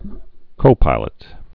(kōpīlət)